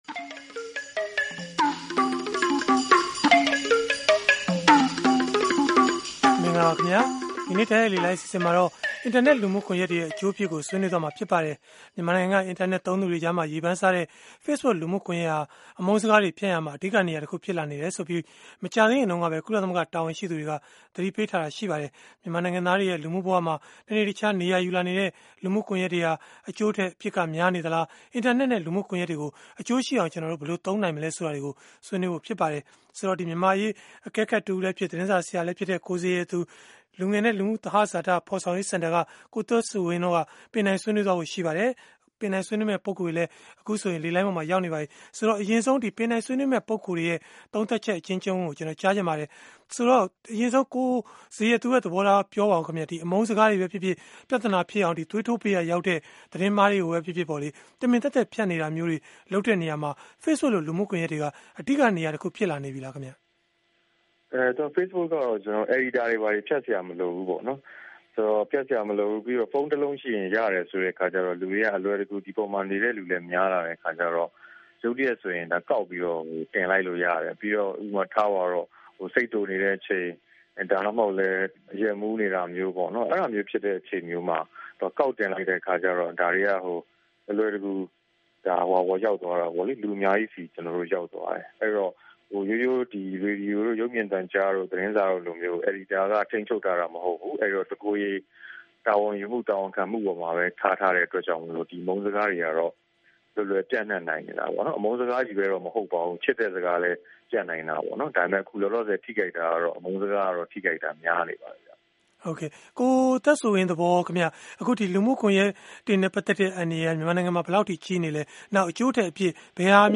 ဗွီအိုအေရဲ့ စနေနေ့ည တိုက်ရိုက်လေလှိုင်း အစီအစဉ်မှာ